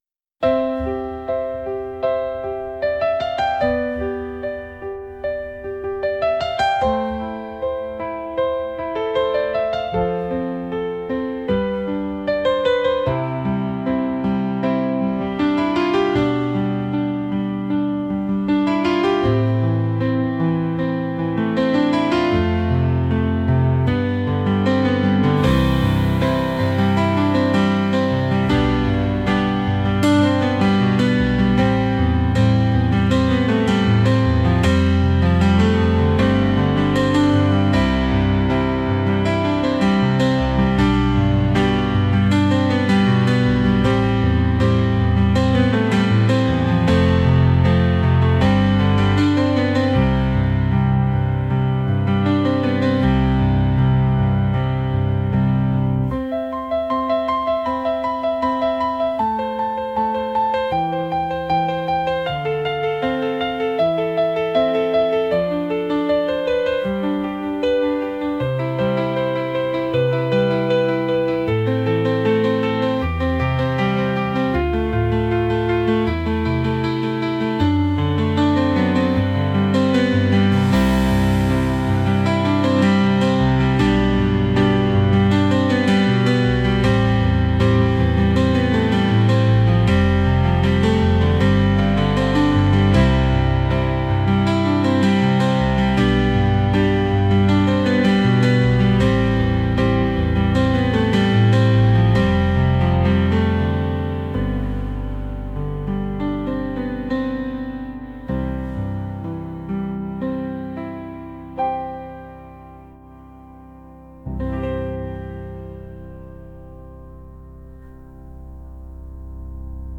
安心できる場所に辿り着いたときのBGM